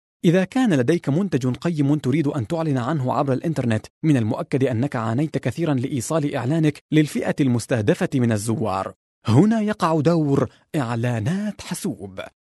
Arabian voiceover talent with warm and confident voice
Sprechprobe: Industrie (Muttersprache):